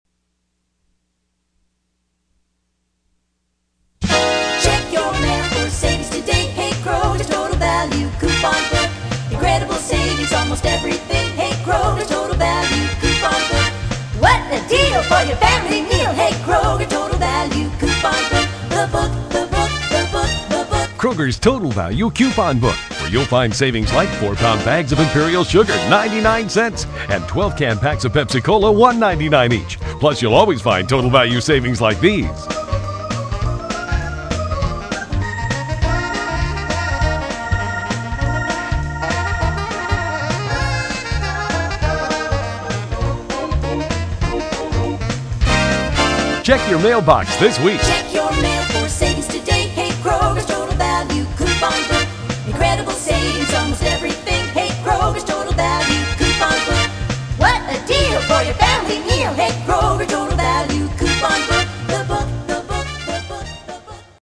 Radio Spot #1,
THEBOOKJINGLE.mp3